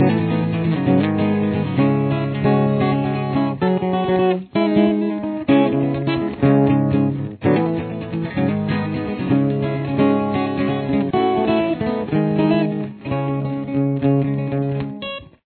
CAPO – Fourth Fret
Choruses 1 and 2